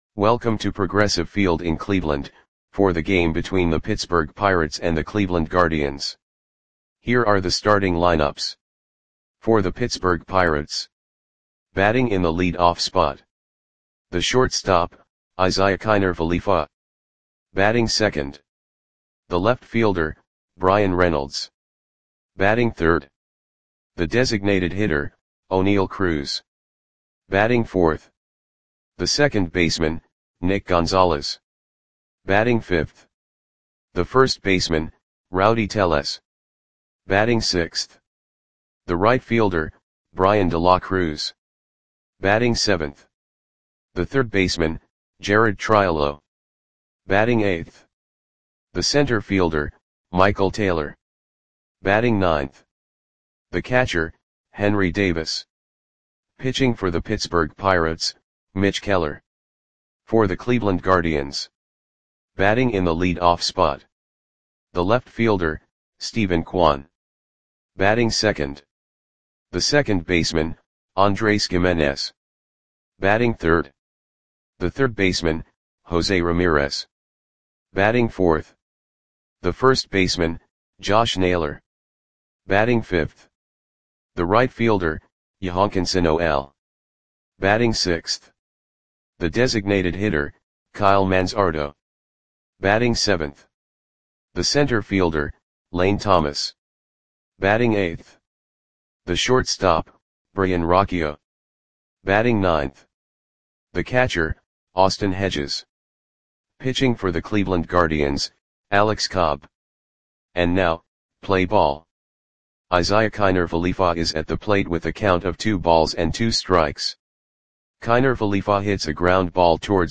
Audio Play-by-Play for Cleveland Indians on September 1, 2024
Click the button below to listen to the audio play-by-play.